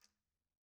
Quinto-Tap1_v1_rr1_Sum.wav